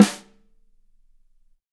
Drums